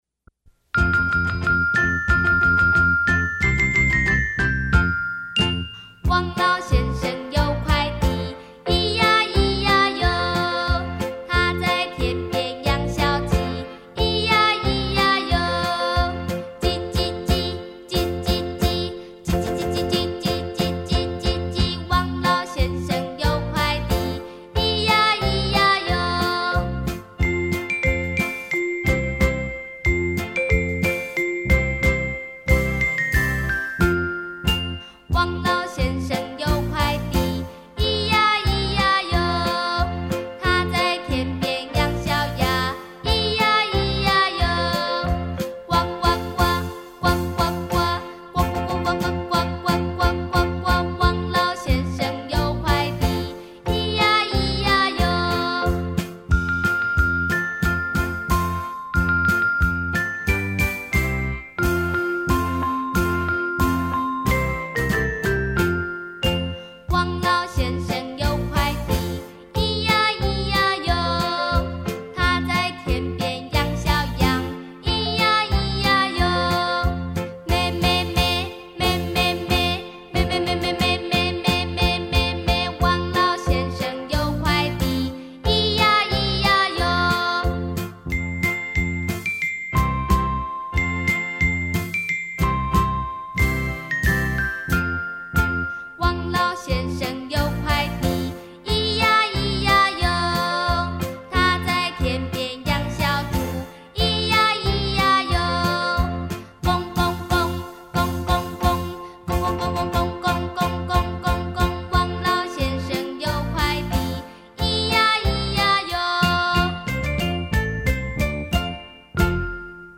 儿歌